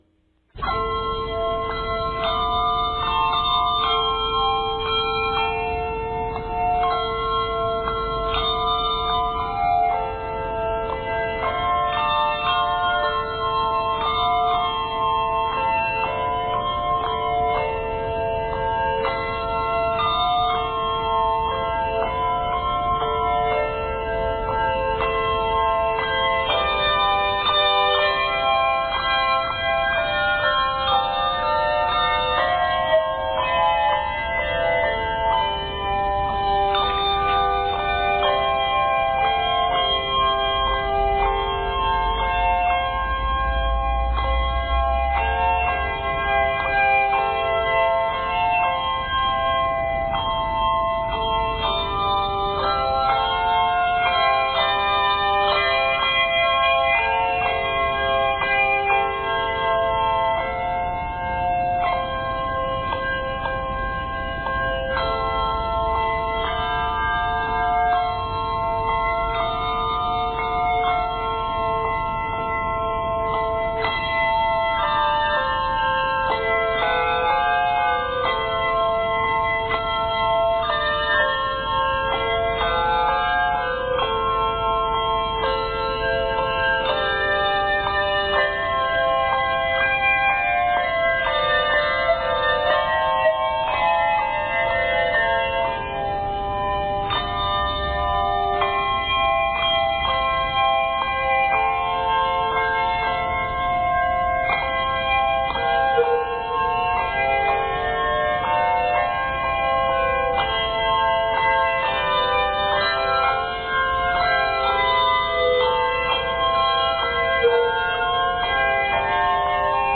Octaves: 2